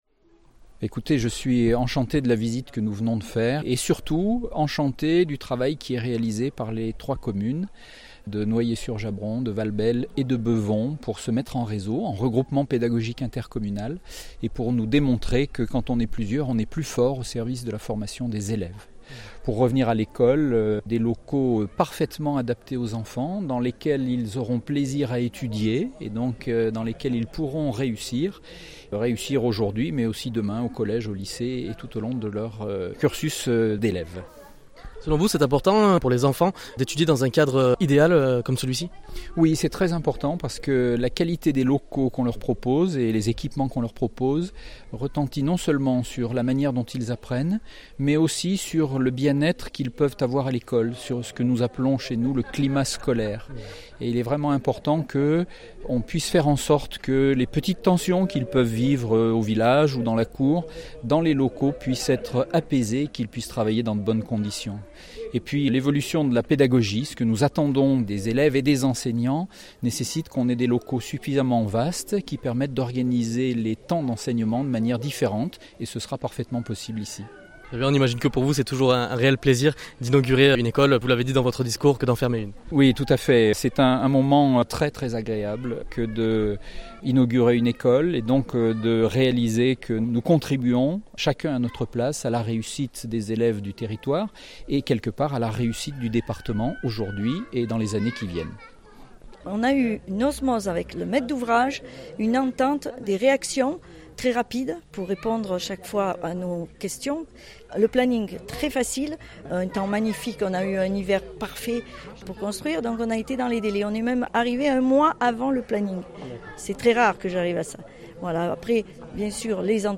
Nous entendrons également la joie des enfants ainsi que leur interprétation sans fausses notes de notre hymne national. écouter ou télécharger Durée : 6'18" 2016-09-21 - Noyers - Inauguration Ecole.mp3 (2.99 Mo)